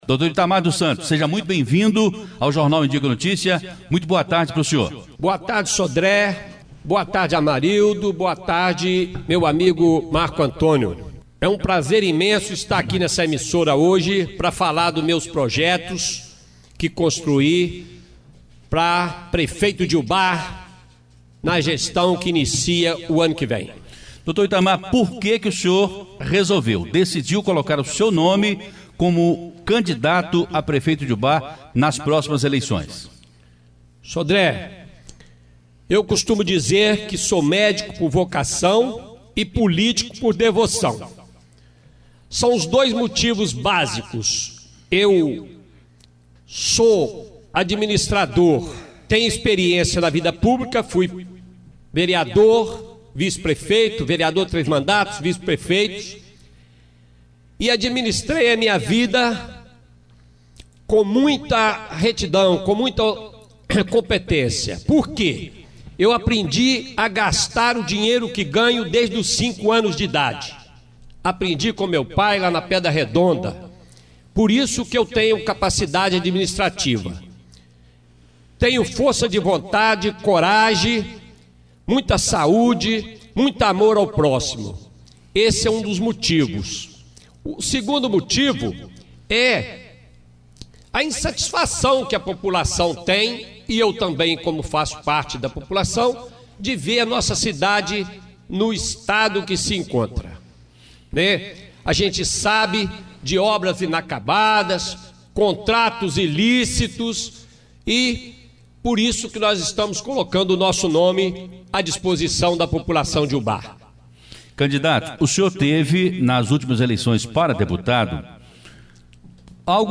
A Rádio Educadora iniciou nesta quarta-feira(14/10) o quadro – EDUCADORA NA BOCA DA URNA – Os candidatos a Prefeito nesta eleição serão entrevistados no Jornal Em dia com a Notícia.
Educadora na boca da urna!Entrevista às 12h30.